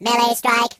project_files/AudioMono/Sounds/voices/Robot/Firepunch6.ogg